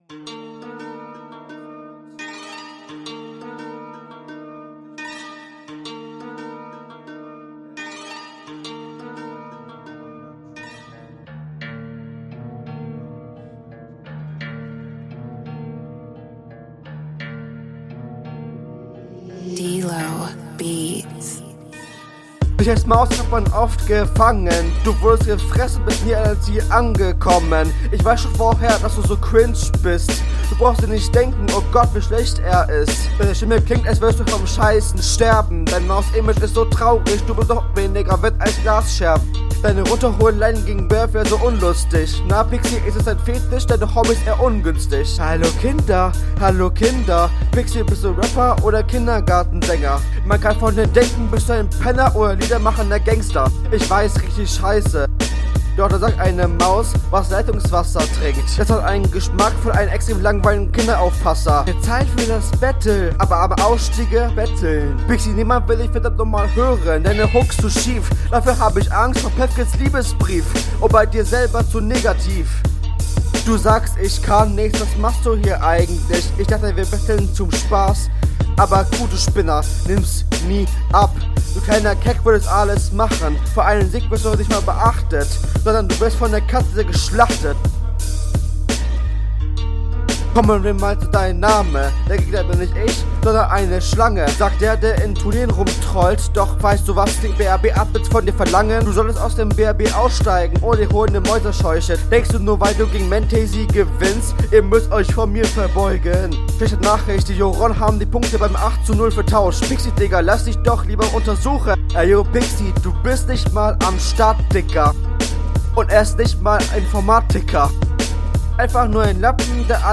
Flow: sehr offbeat, guck den tipp an den ich bei deiner rr geschrieben hab' Text: …